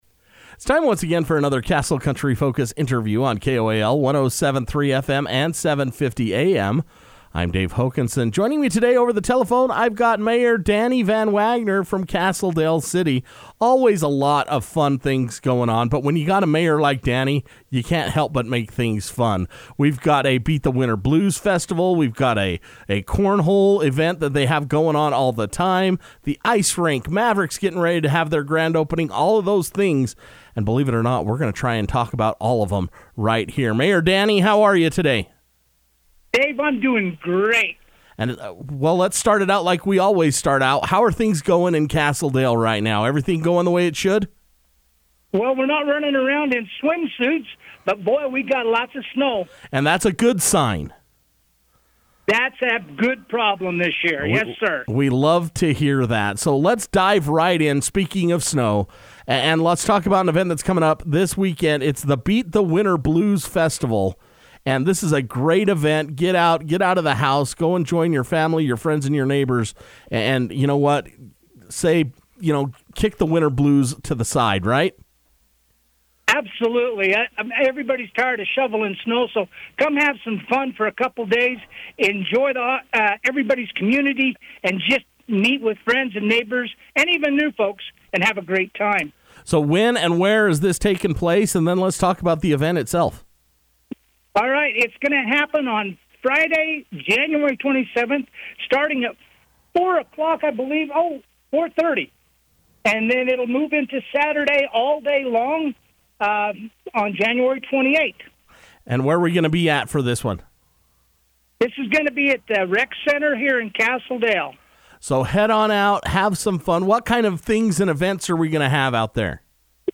Castle Dale City Mayor Danny VanWagoner talks Beat the Winter Blues Festival